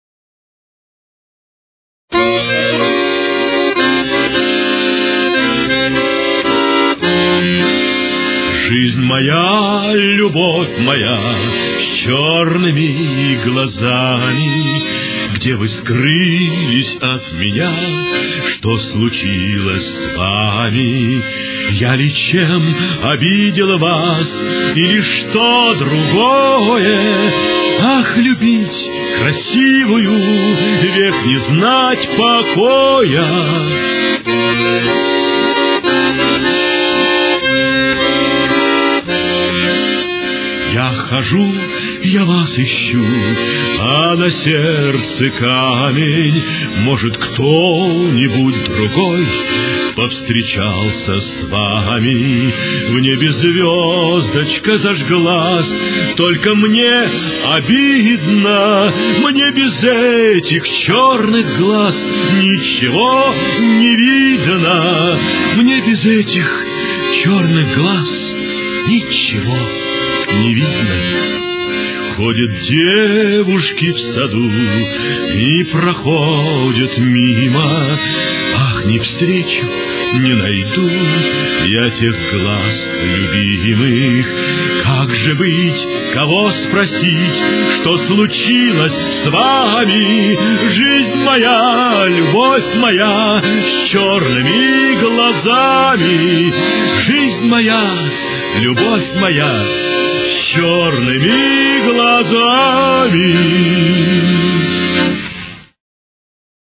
Фа минор. Темп: 113.